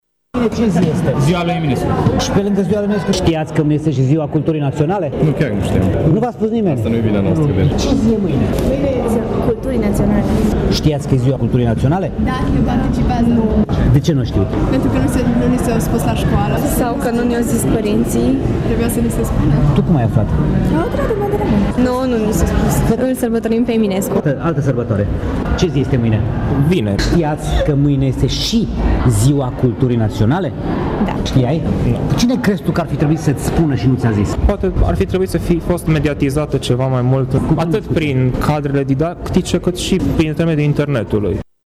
Dintre târgumureșenii întrebați, unii au spus că mâine e… vineri, alții au știut că e ziua de naștere a lui Mihai Eminescu, însă puțini au aflat că de 6 ani serbăm și Ziua Culturii Naționale: